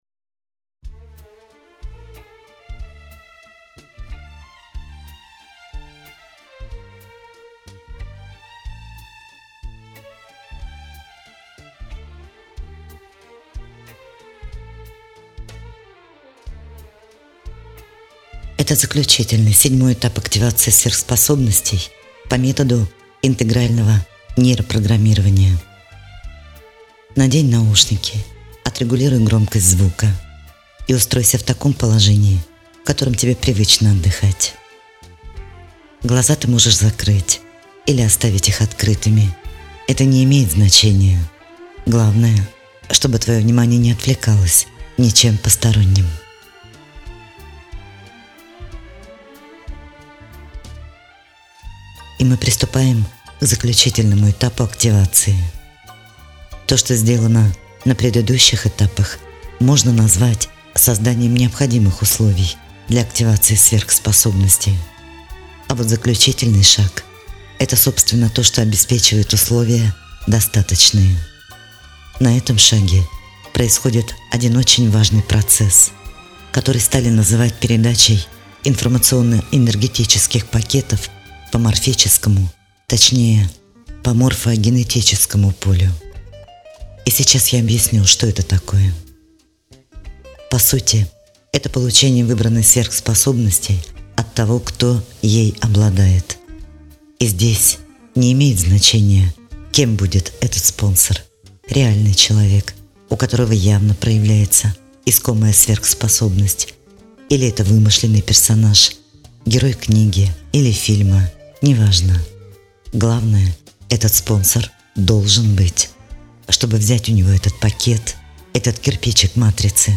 Это и становится основой того, что является осуществлением неосуществимого» Формат 7-го этапа активации – аудиосеанс.